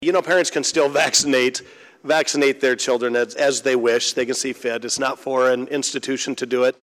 Republican Senator Jason Schultz of Schleswig says the law gives individuals and parents the right to make their own decisions about COVID-19 shots.